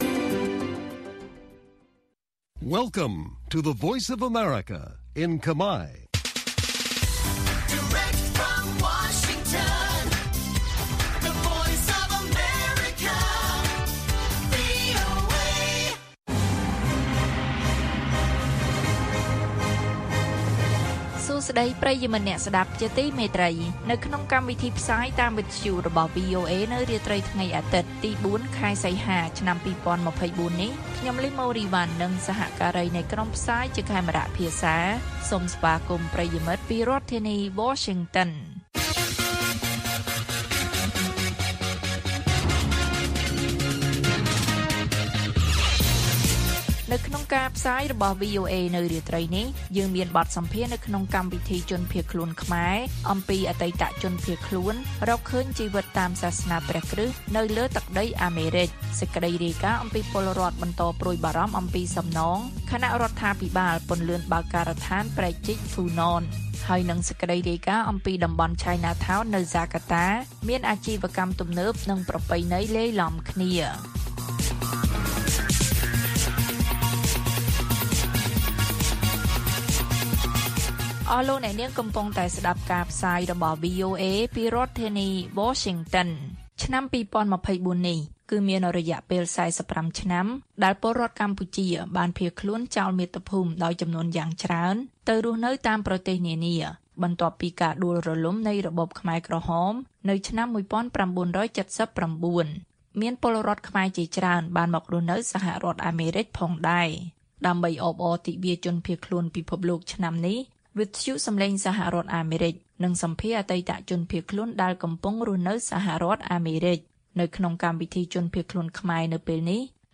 ព័ត៌មាននៅថ្ងៃនេះមាន បទសម្ភាសន៍នៅក្នុងកម្មវិធីជនភៀសខ្លួនខ្មែរ៖ អតីតជនភៀសខ្លួនរកឃើញជីវិតតាមសាសនាព្រះគ្រឹស្តនៅលើទឹកដីអាមេរិក។ ពលរដ្ឋបន្តព្រួយបារម្ភអំពីសំណង ខណៈរដ្ឋាភិបាលពន្លឿនបើកការដ្ឋានព្រែកជីកហ្វូណន។ តំបន់ Chinatown នៅហ្សាការតាមានអាជីវកម្មទំនើបនិងប្រពៃណីលាយឡំគ្នា៕